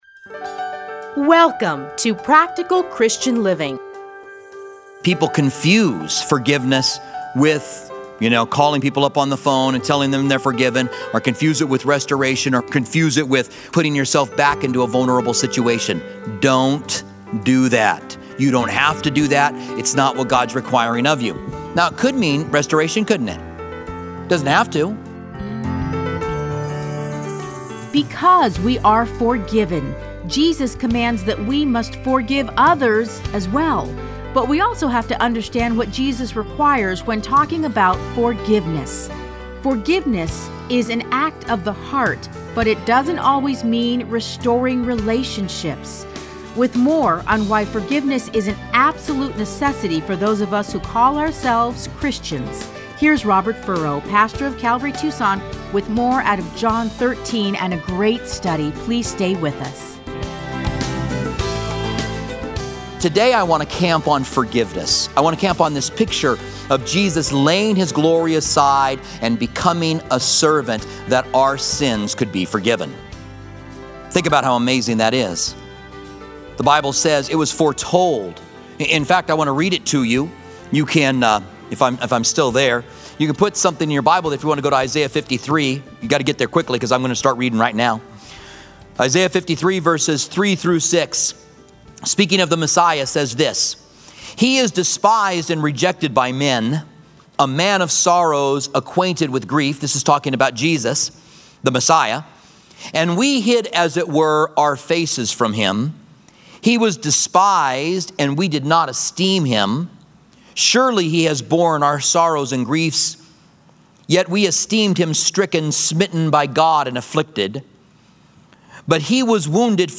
Listen to a teaching from John 13:1-10.